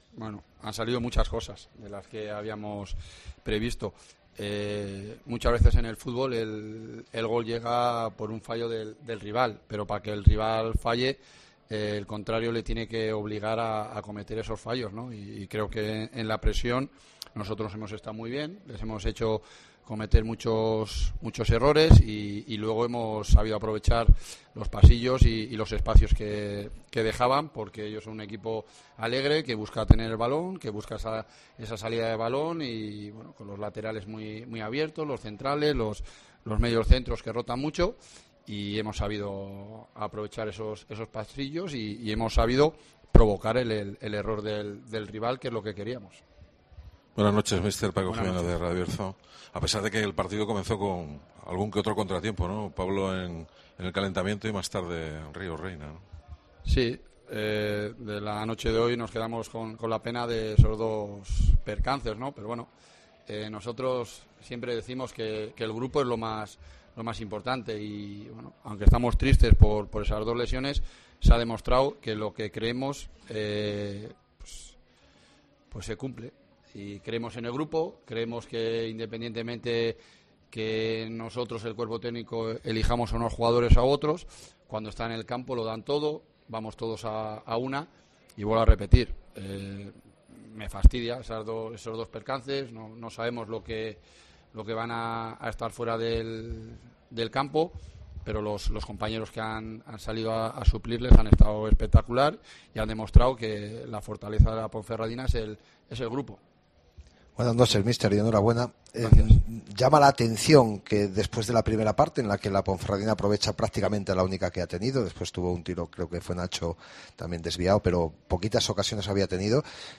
Escucha aquí las palabras del míster de la Deportiva Ponferradina tras la victoria 4-0 ante el Tenerife